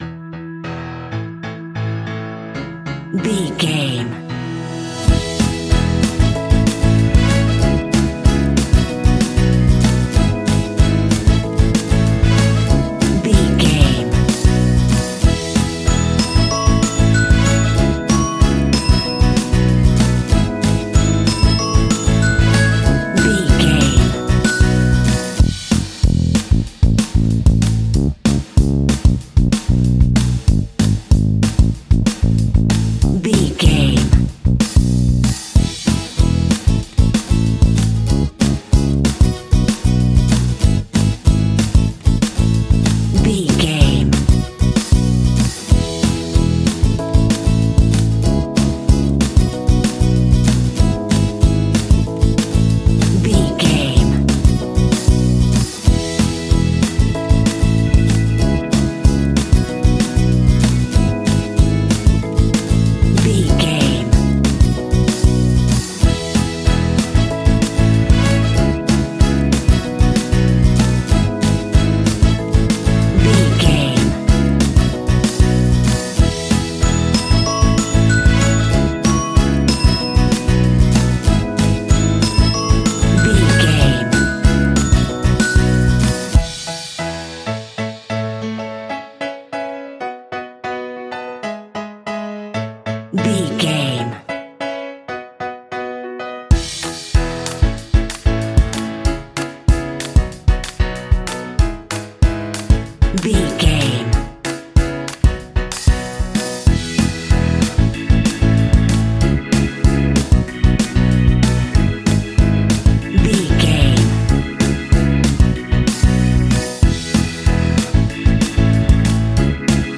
Ionian/Major
DOES THIS CLIP CONTAINS LYRICS OR HUMAN VOICE?
electro pop
pop rock
happy
upbeat
bouncy
drums
bass guitar
electric guitar
keyboards
hammond organ
acoustic guitar
percussion